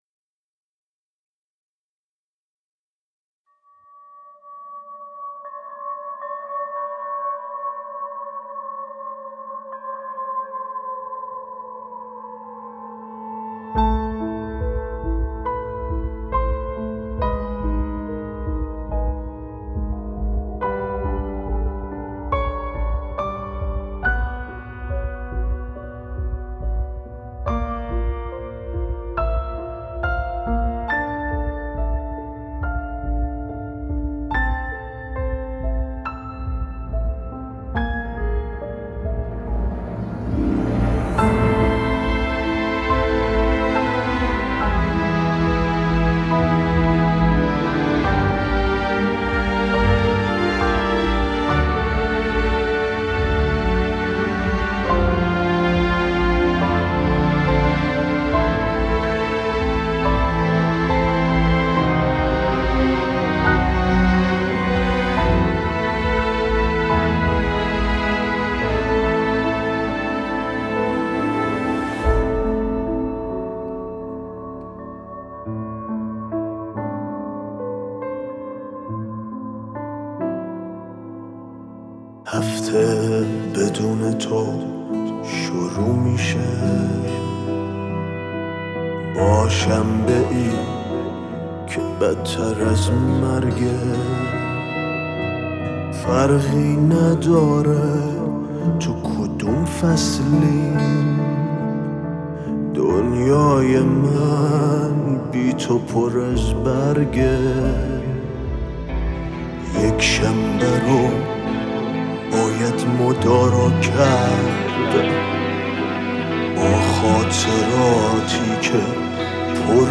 در فضایی عاشقانه جریان دارد